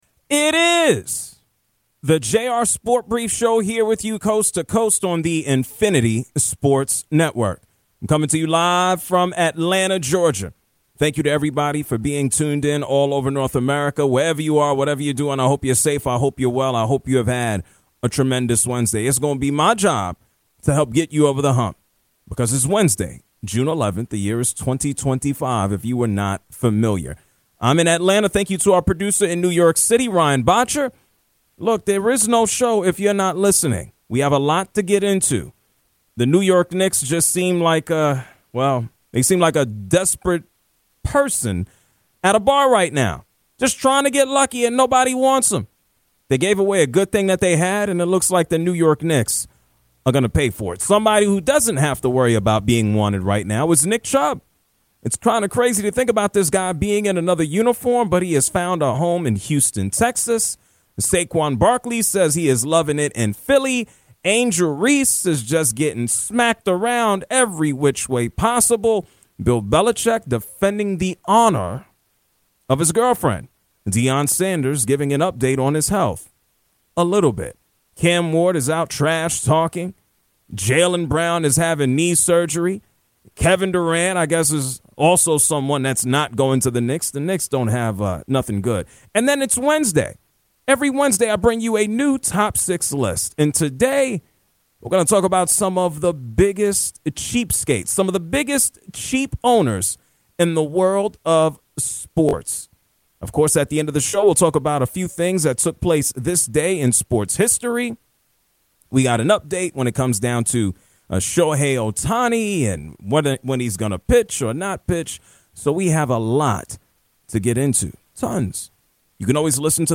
| A caller tries to defend the Indiana Pacers | Nick Chubb on finding a new home in Houston.